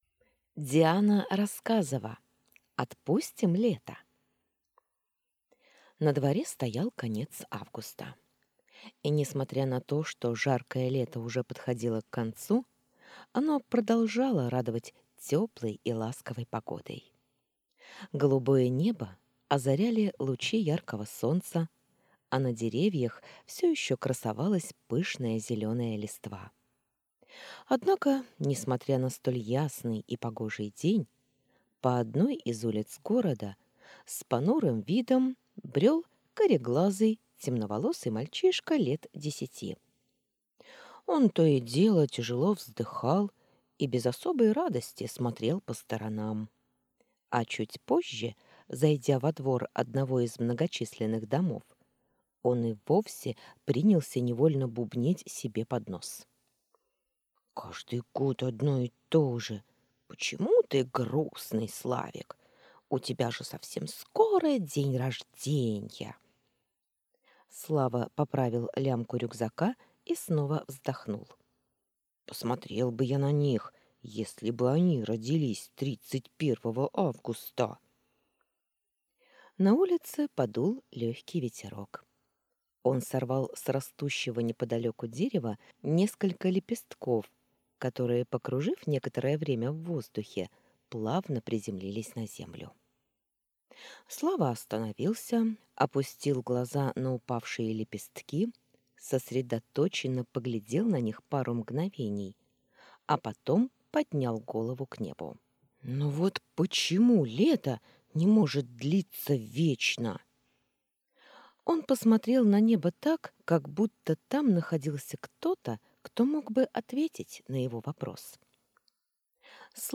Аудиокнига Отпустим лето?